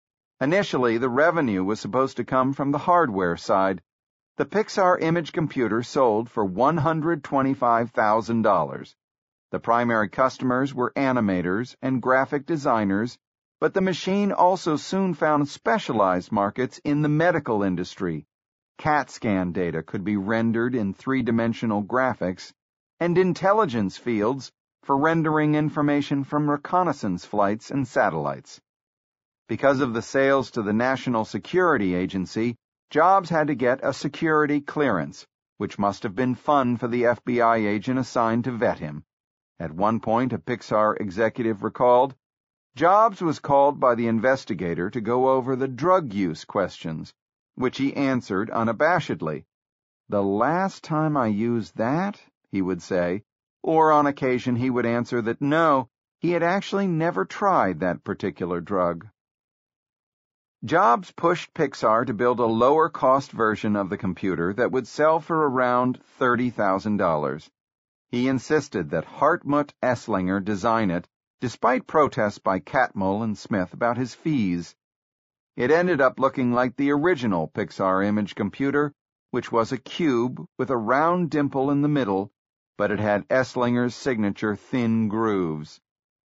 在线英语听力室乔布斯传 第259期:卢卡斯影业的电脑部门(5)的听力文件下载,《乔布斯传》双语有声读物栏目，通过英语音频MP3和中英双语字幕，来帮助英语学习者提高英语听说能力。
本栏目纯正的英语发音，以及完整的传记内容，详细描述了乔布斯的一生，是学习英语的必备材料。